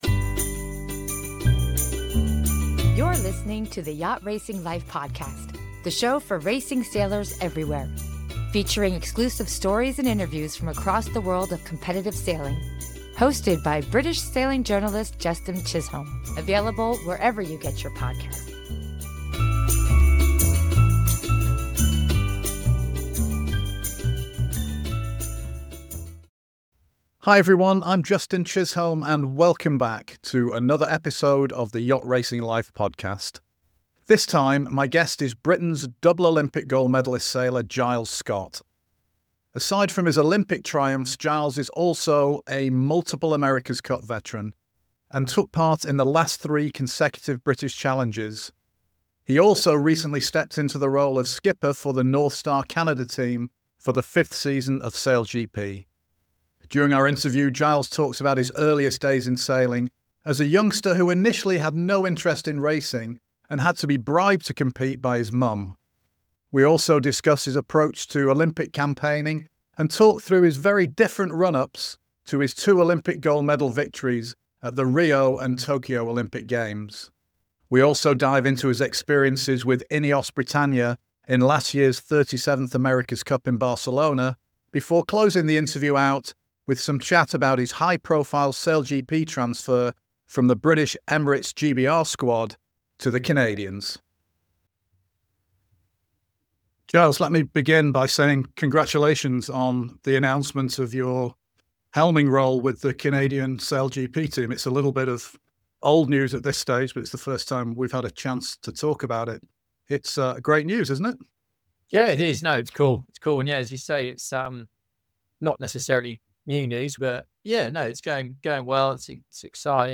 Aside from his Olympic triumphs Giles is also an multiple America’s Cup veteran – including taking part in the last three consecutive British challenges – and recently stepped into the role of skipper of the Northstar Canada team for the fifth season of SailGP. During the interview – recorded in early January 2025 – Giles talks about his earliest days in sailing as a youngster who initially had no interest in racing and had to be bribed to compete by his mum.